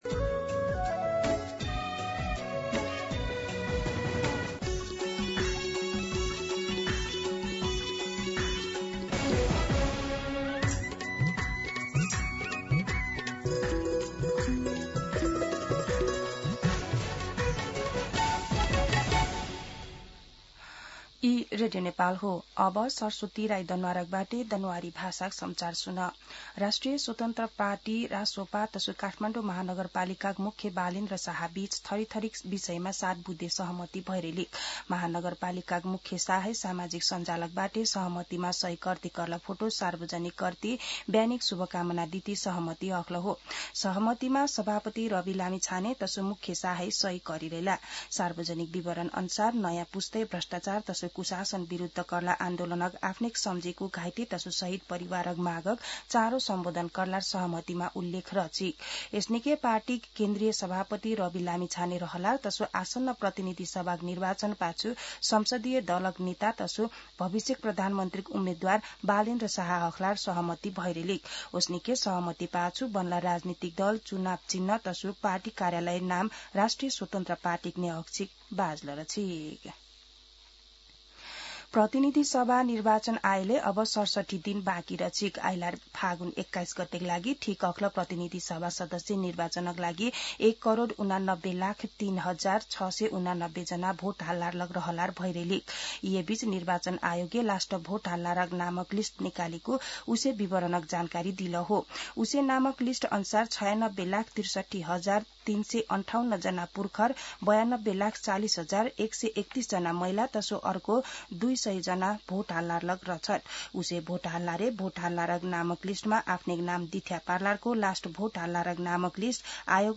दनुवार भाषामा समाचार : १३ पुष , २०८२
Danuwar-News-13.mp3